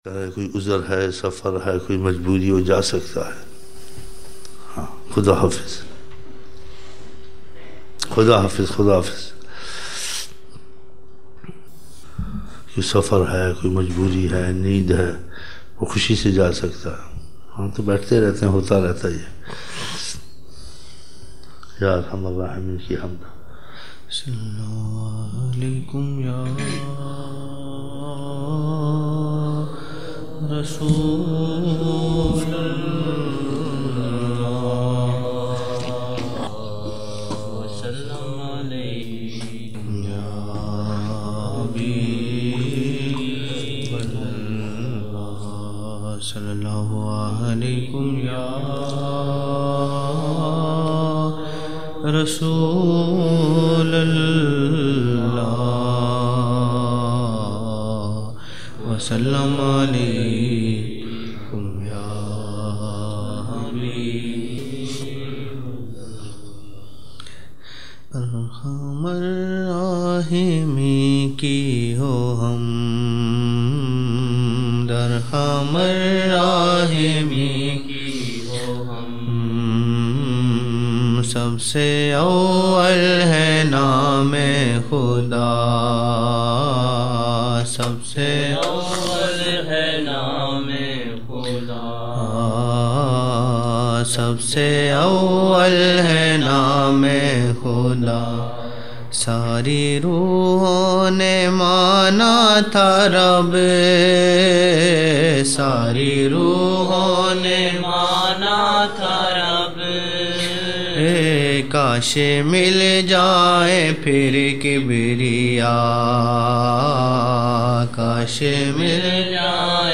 24 November 1999 - Fajar mehfil (16 Shabaan)